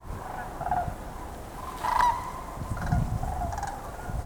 On the morning of February 2nd we had six or seven very large flocks of Sandhill Cranes flying north over our house. As usual, we heard them before we saw them.
call sounds a little bit like an open cedar box struck with a vibrating striker.
We are always surprised at how far the call carries.